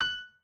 piano2_6.ogg